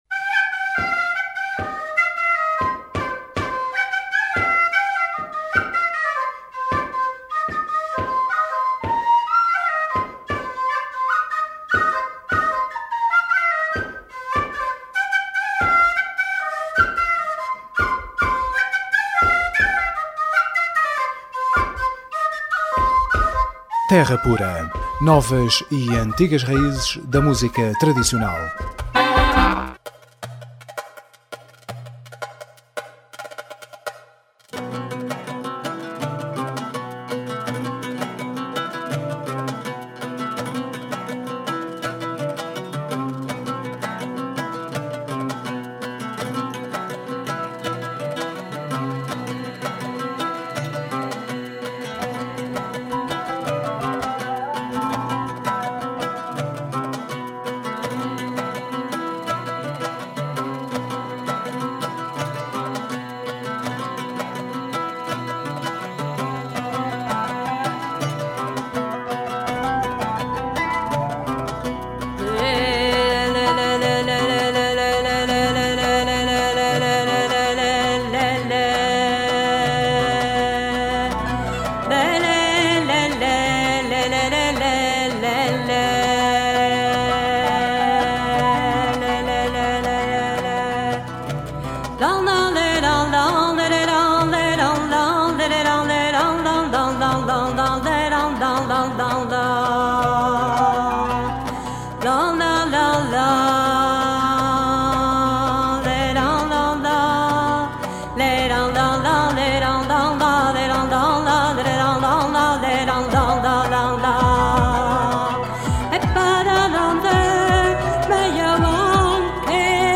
Terra Pura 22ABR13: Entrevista Astrakan Project